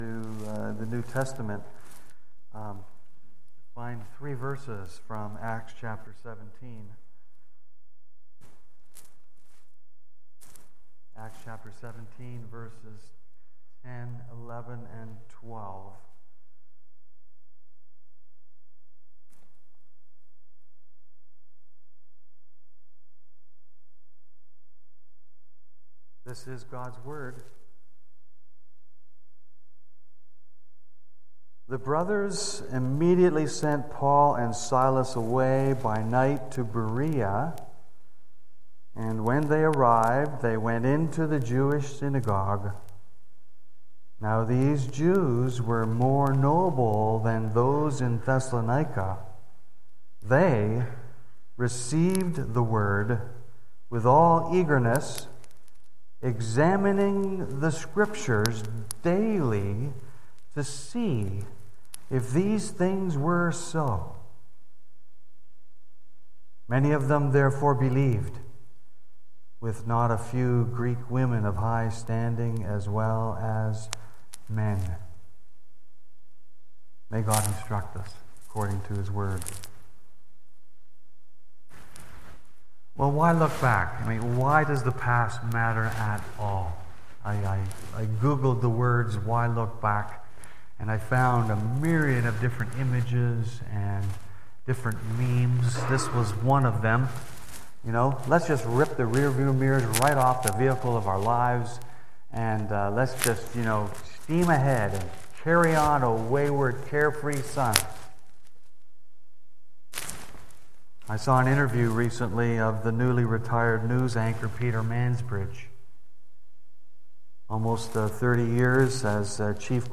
Lady Jane Grey BACK TO SERMON LIST Preacher